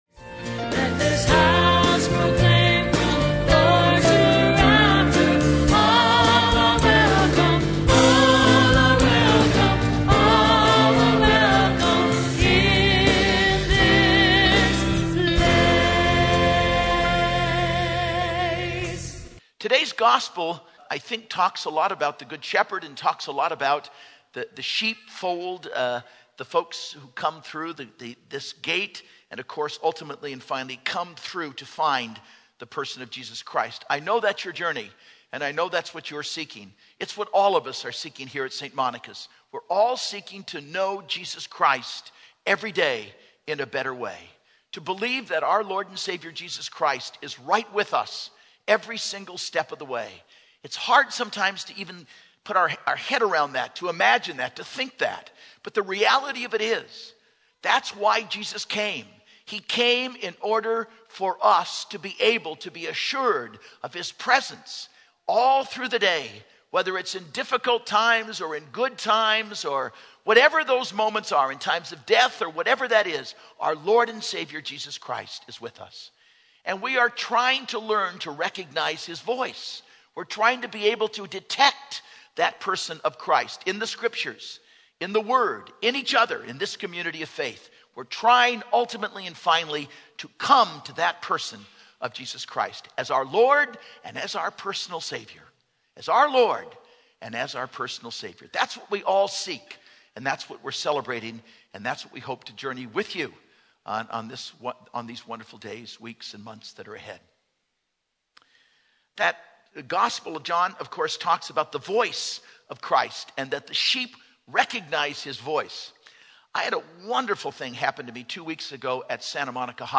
Homily - 2/22/15 - 1st Sunday of Lent